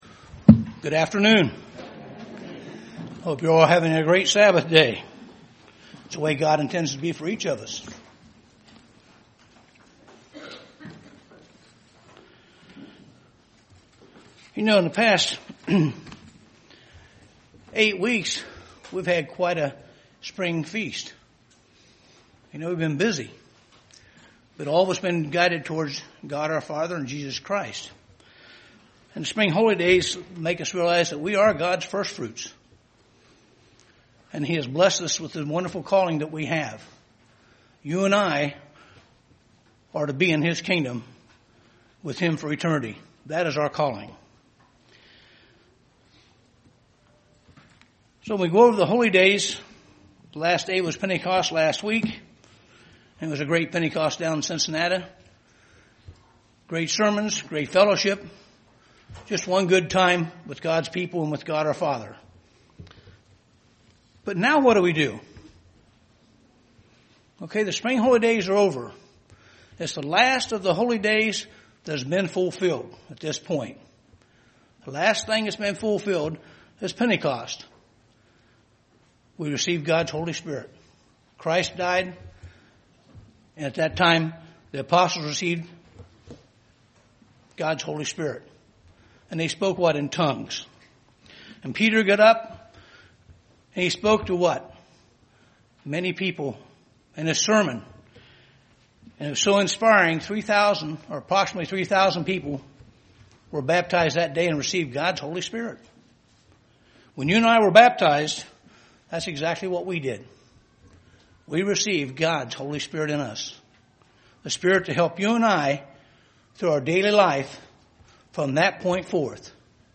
Given in Dayton, OH
Let us not loose focus during this time UCG Sermon Studying the bible?